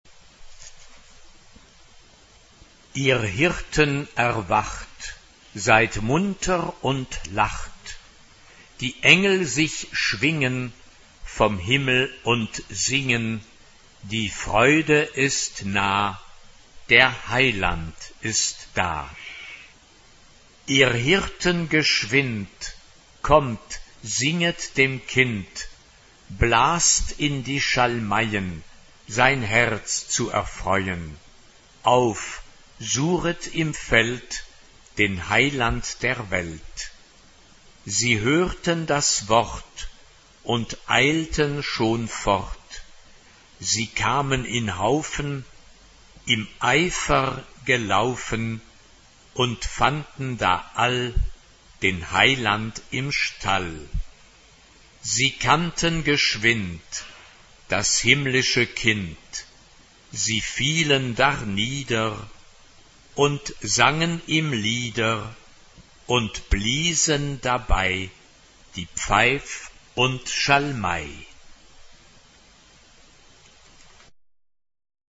Tonality: D major